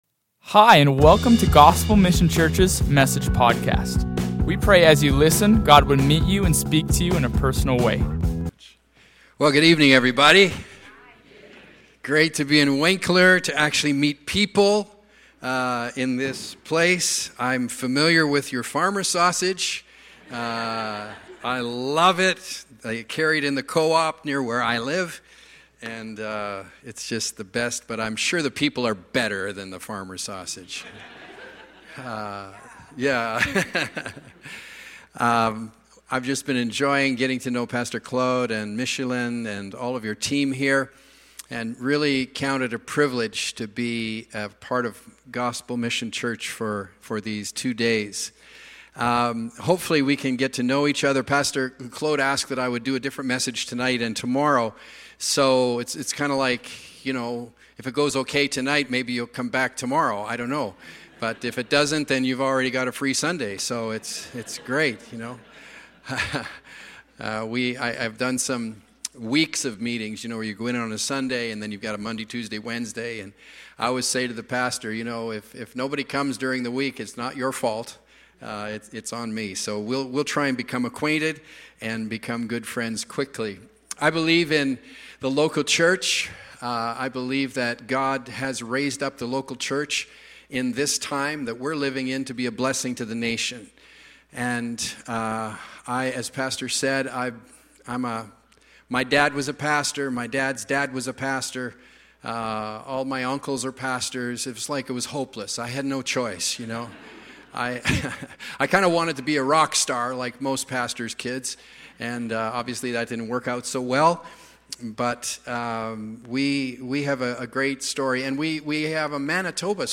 Gospel Mission Church